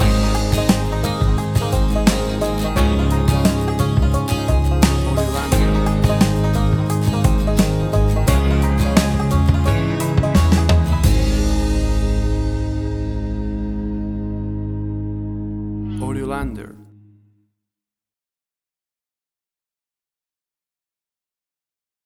great for fun upbeat country music parties and line dances.
Tempo (BPM): 87